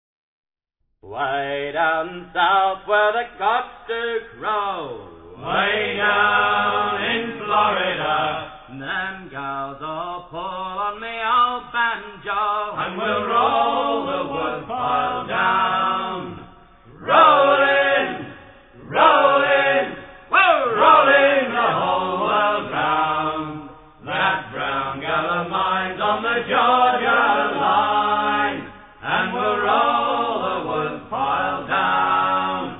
in front of a select audience